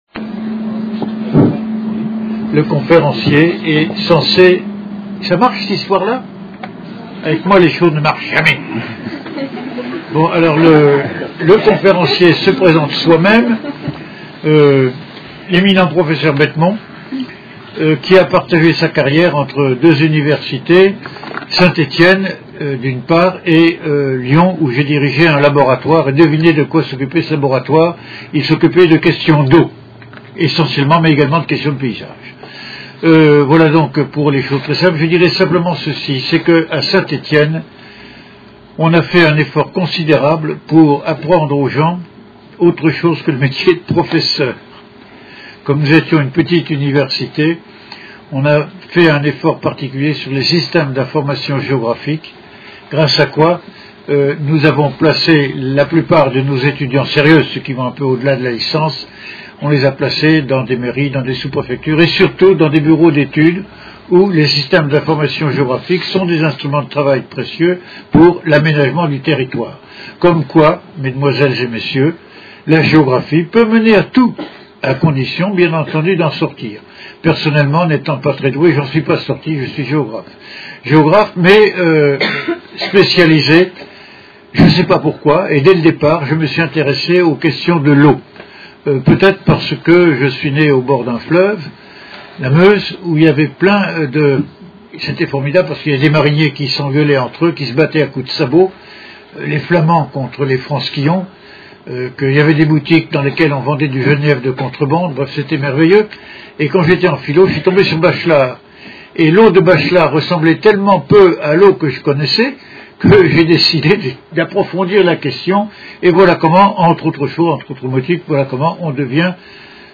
COLLOQUE DES COMPAGNIES DES EXPERTS DE JUSTICE DU GRAND-ESTOrganisé par la Compagnie de ReimsSous la présidence d’honneur de Monsieur le Premier Président et de Monsieur le Procureur Général de la Cour d’Appel de ReimsTitre: Approche comparée de l'expertise dans les systèmes judiciaires de droit continental et de common lawIntervenant: Monsieur Patrick MATET, Président de chambre à la Cour d’appel de Paris